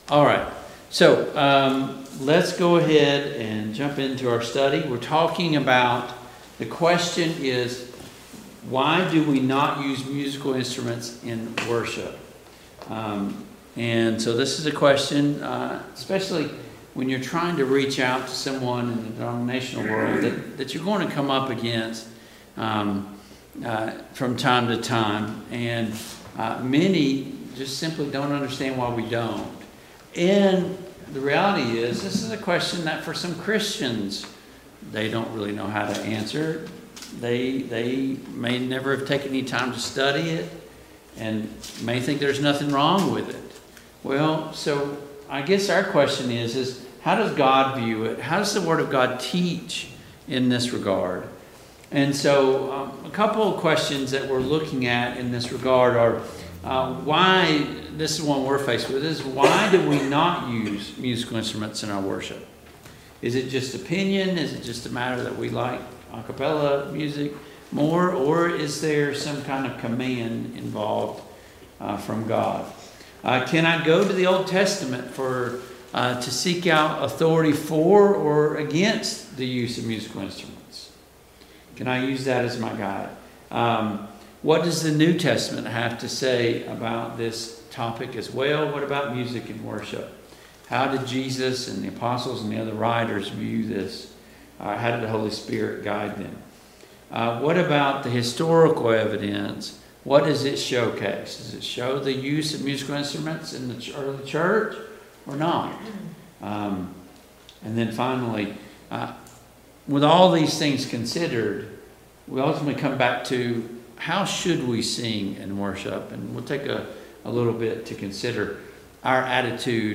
Ephesians 5:18-20 Service Type: Mid-Week Bible Study Download Files Notes Topics